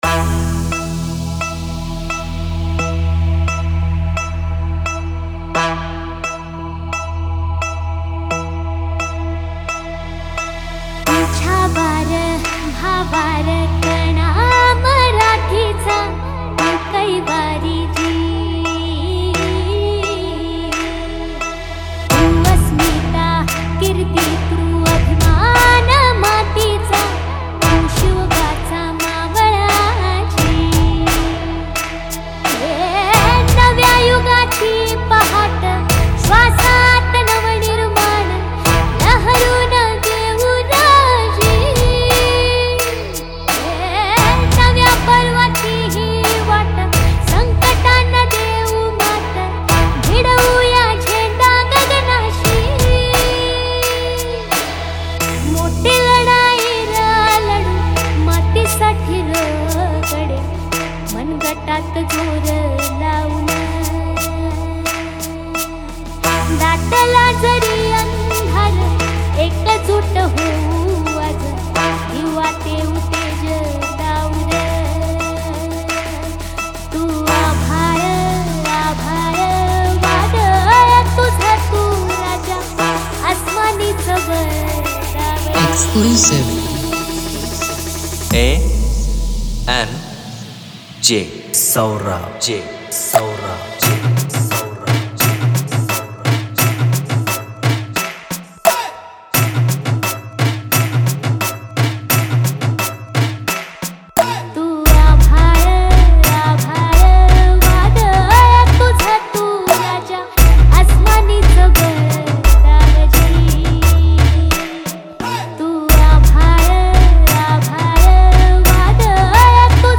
• Category: MARATHI SOUND CHECK